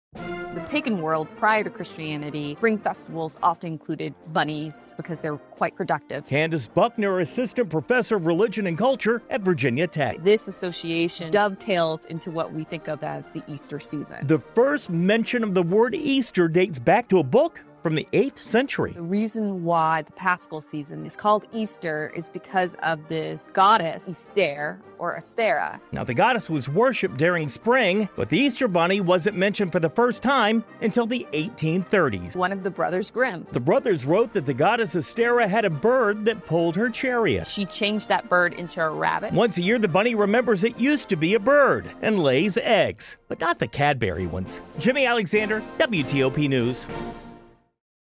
Live Radio
“Once a year, (the rabbit) remembers it used to be a bird and lays eggs,” she said with a laugh.